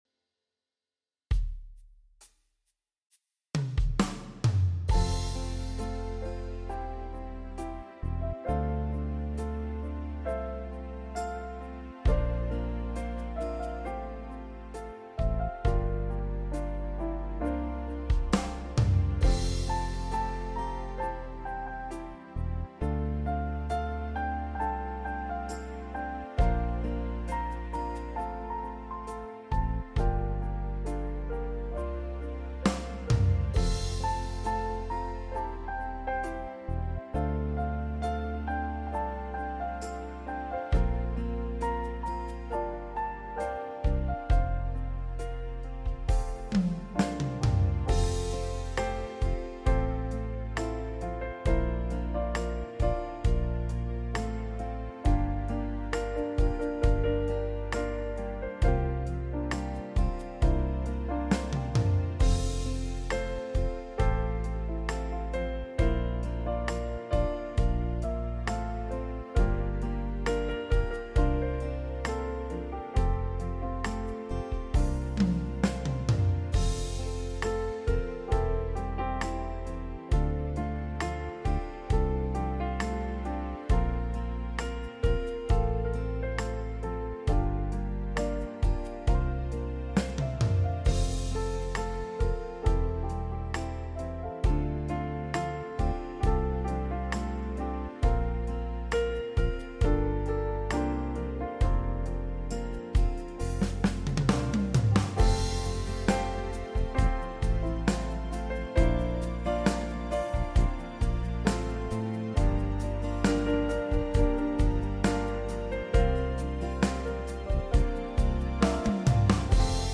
yamaha psr sx900연주